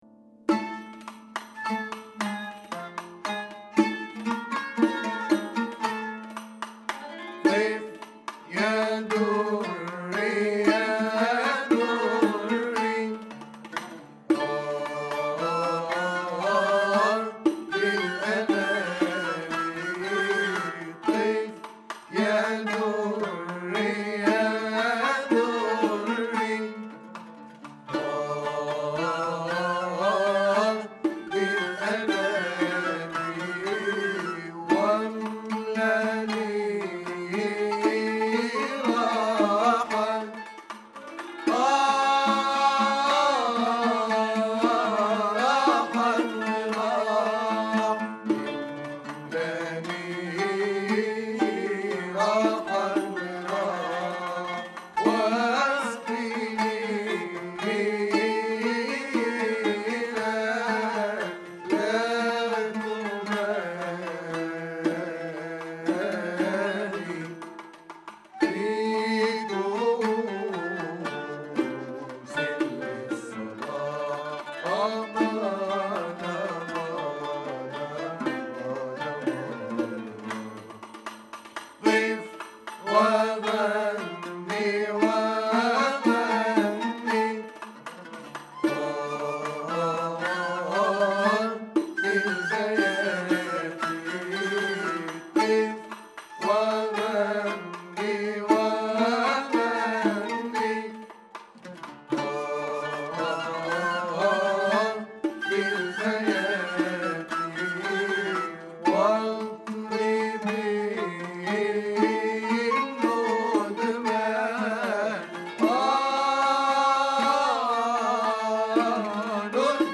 A really beautiful Muwashshah in 13-beat rhythm.
Maqam Hijazkar-Kurd